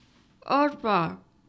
ボイス
女性挨拶